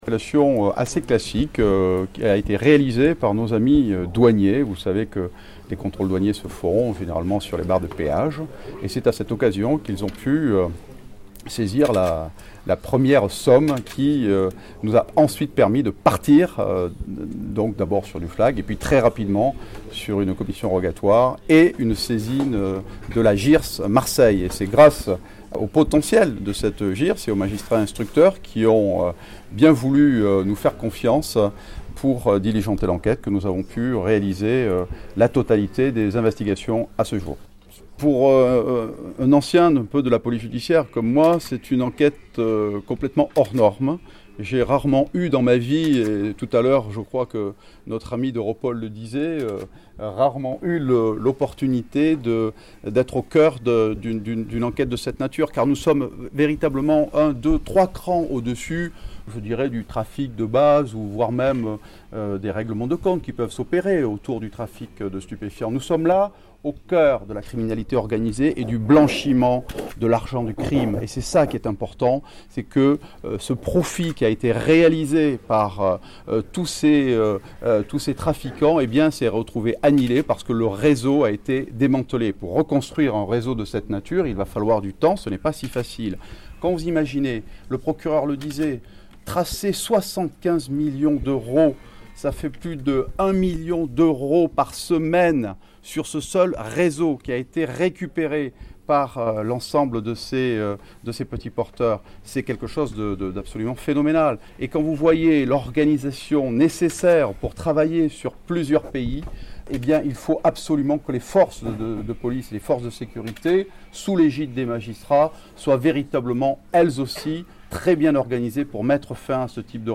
Réunis à l’occasion d’une conférence de presse ce mardi 29 novembre à Marseille (Bouches-du-Rhône), des magistrats et policiers ayant participé à l’enquête parlent d’une «affaire hors normes».